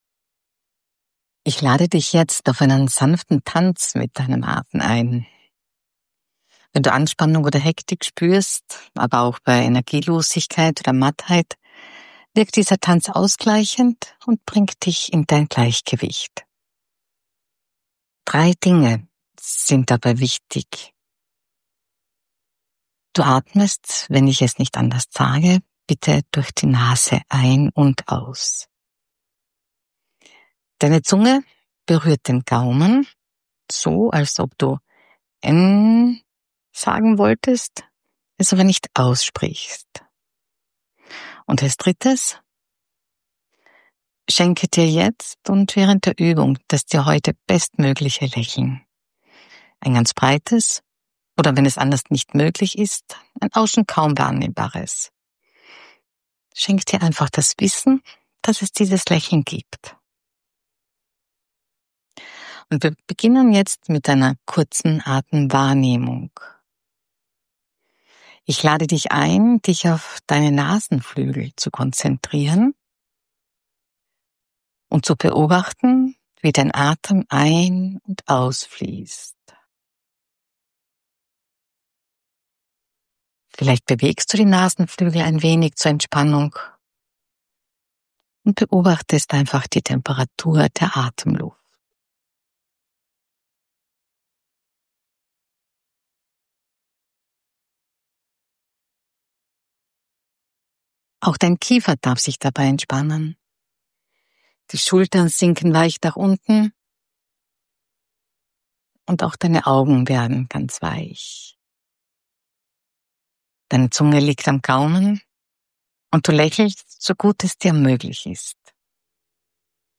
Atemübung im 4-4-Rhythmus – ausgleichend ~ Einfach DurchAtmen Podcast
Durch den Rhythmus von 4 Sekunden Ein- und 4 Sekunden Ausatmen kommt dein Nervensystem ins Gleichgewicht: Stress reduziert sich, Herzschlag und Gedanken beruhigen sich, du fühlst dich ausgeglichener und klarer.